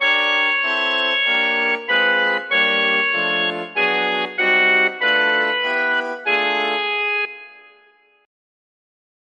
Simplicidade: tr�s exemplos complexos ou Complexidade: tr�s exemplos simples [ anterior ] [ pr�xima ] Capa Exemplo 1 Exemplo 2 Exemplo 3 [ ouvir ] e, invertendo-se os acordes, reforce-se a sensa��o de movimento contr�rio.